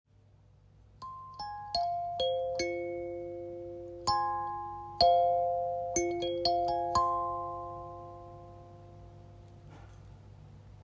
Kalimba 5 blades
• Chord: A#", do", fa', fa", do"'
• Key: F minor
Intuitive, soft and harmonious melody!
Mahogany wood resonance body providing harmonious tones
Very well tuned in F minor, perfect tuning: allows easy and intuitive playing.